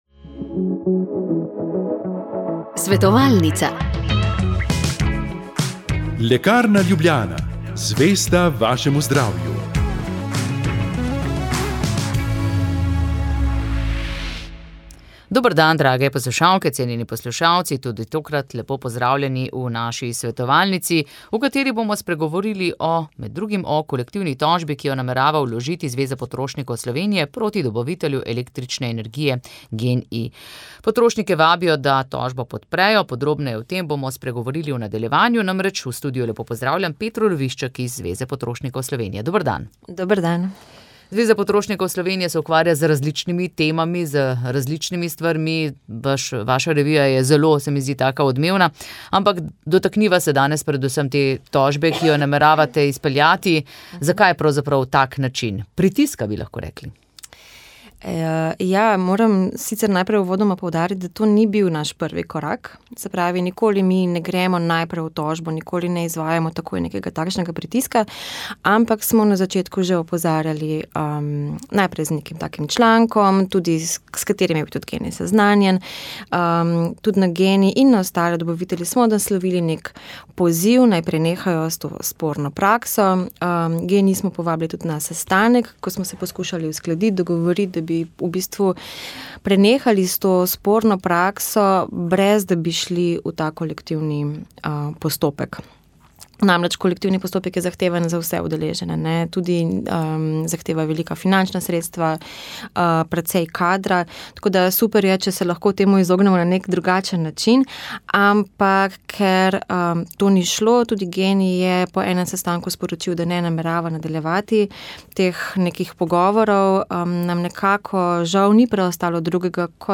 Sv. maša iz cerkve Marijinega oznanjenja na Tromostovju v Ljubljani 9. 6.